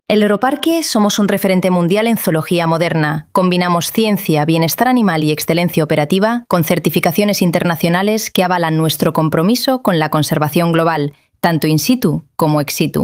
Muestra de voces con IA
Voces femeninas
Nasal, natural y clara